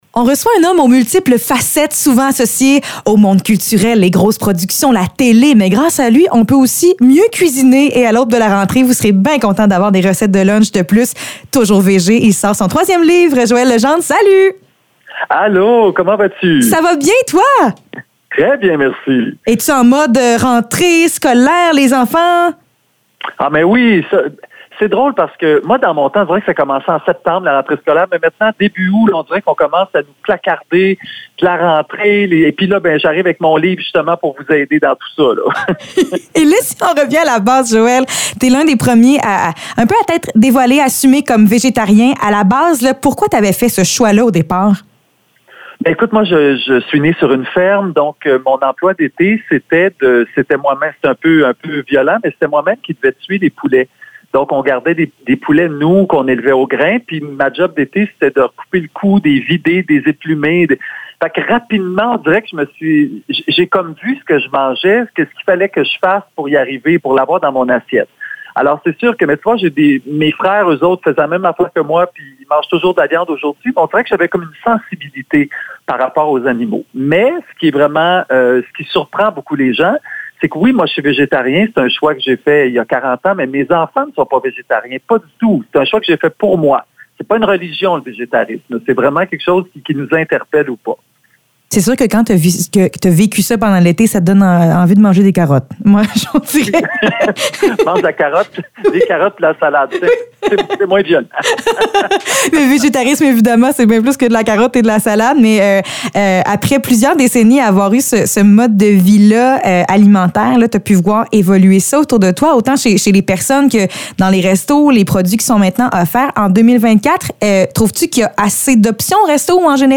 Entrevue avec Joël Legendre